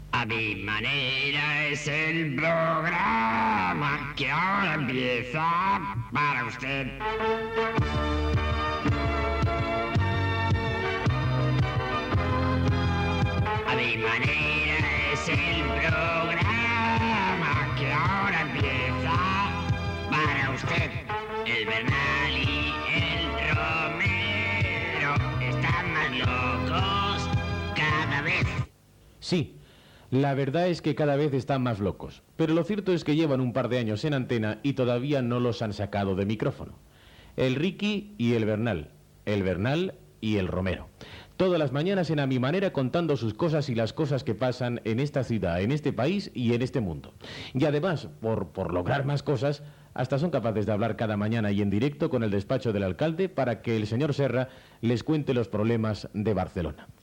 Sintonia cantada del programa i comentari sobre el programa i els seus dos anys.
Entreteniment